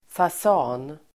Uttal: [fas'a:n]